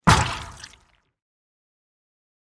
دانلود آهنگ تصادف 8 از افکت صوتی حمل و نقل
جلوه های صوتی
دانلود صدای تصادف 8 از ساعد نیوز با لینک مستقیم و کیفیت بالا